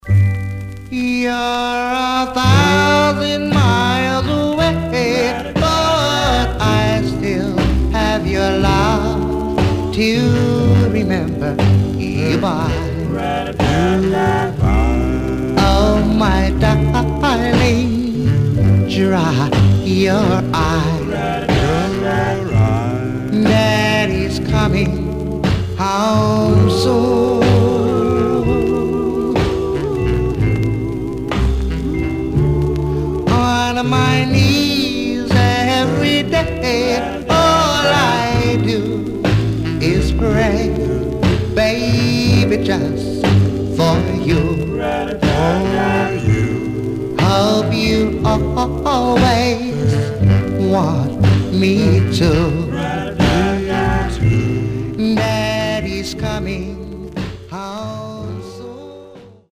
45 RPM on Both Sides Condition: VG+
Some surface noise/wear Stereo/mono Mono
Male Black Groups